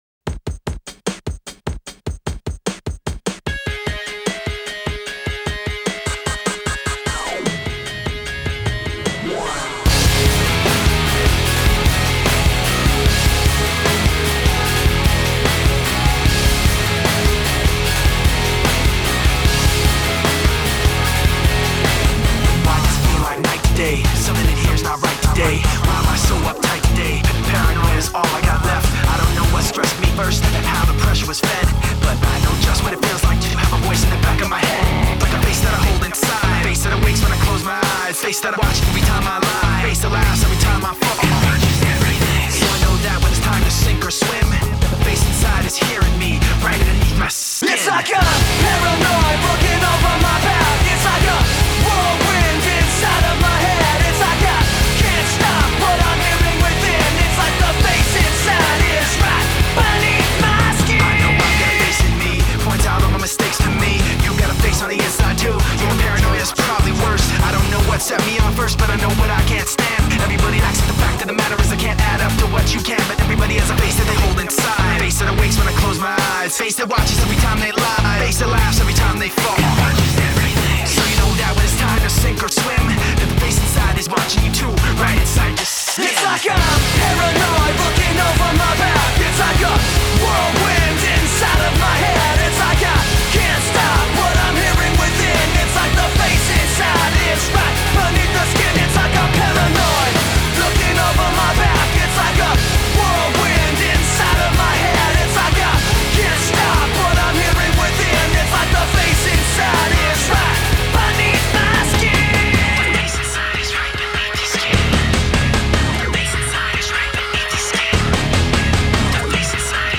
• Жанр: Alternative, Rock